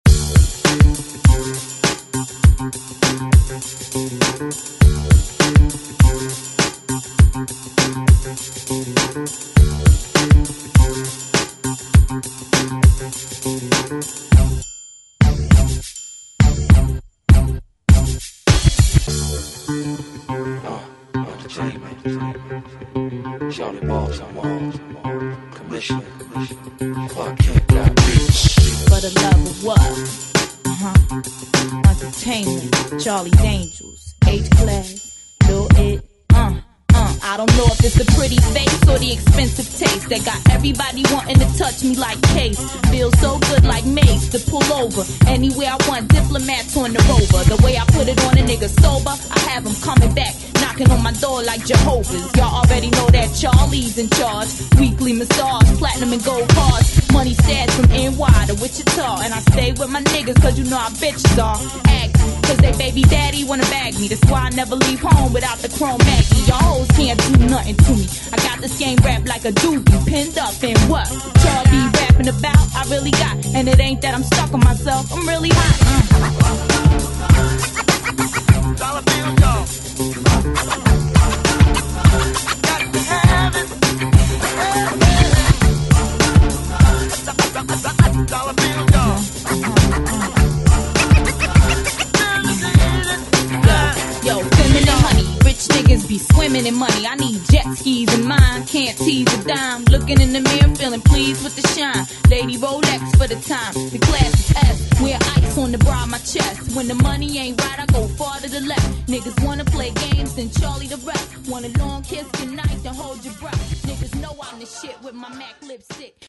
Hip Hop Rap Music Extended Intro Outro
101 bpm